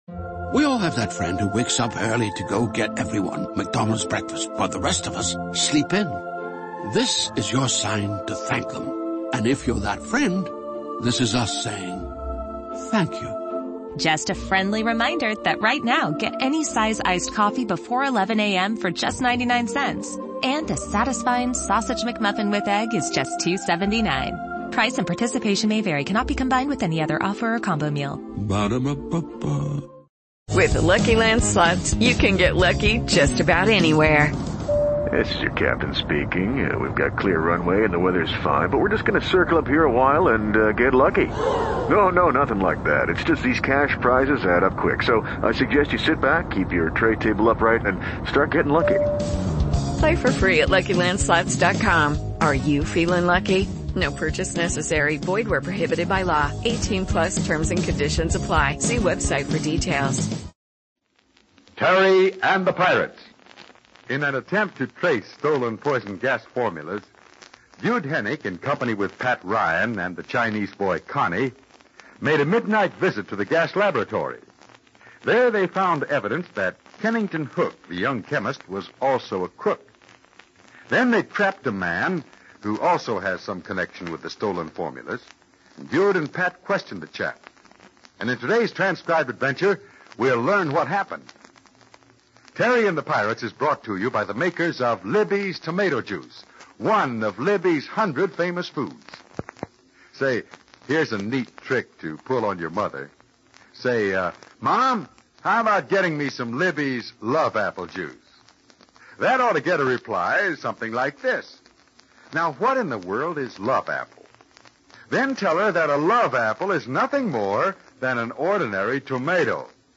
Terry and the Pirates was an American radio serial adapted from the comic strip of the same name created in 1934 by Milton Caniff. With storylines of action, high adventure and foreign intrigue, the popular radio series enthralled listeners from 1937 through 1948.